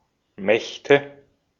Ääntäminen
Ääntäminen Tuntematon aksentti: IPA: /ˈmɛçtə/ Haettu sana löytyi näillä lähdekielillä: saksa Käännöksiä ei löytynyt valitulle kohdekielelle. Mächte on sanan Macht monikko.